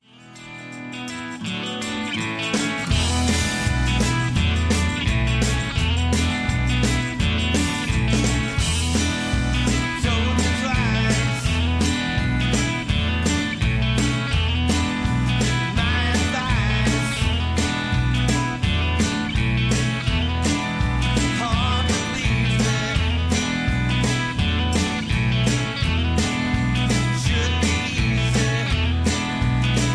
Tags: studio tracks , sound tracks , backing tracks , rock